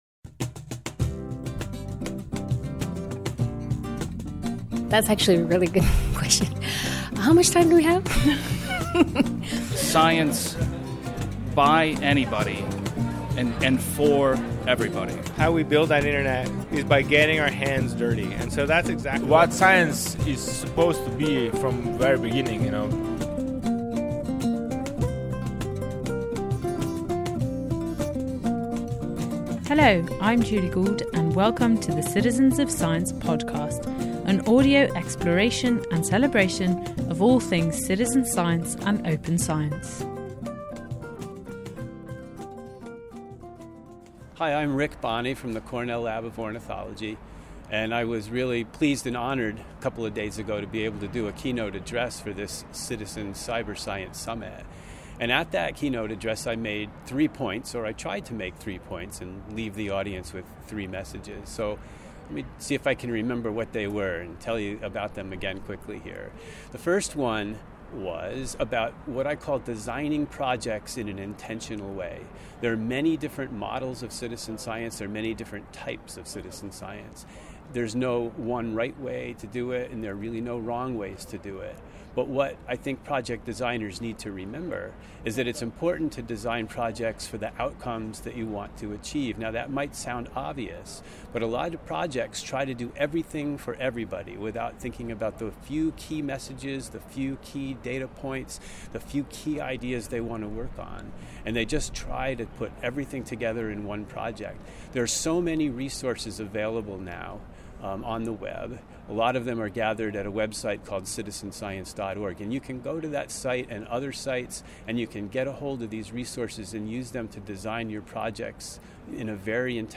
Keynote speech summary